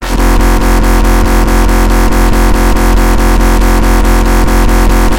简单的DubstepDnB晃动
描述：一个简单的摇摆，加上一点门控混响。
标签： 140 bpm Dubstep Loops Bass Wobble Loops 894.72 KB wav Key : Unknown
声道立体声